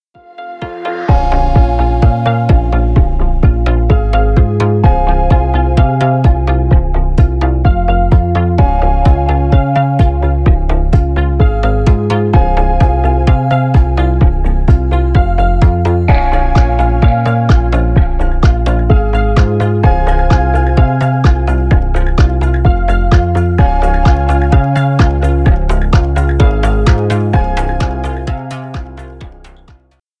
Электроника
без слов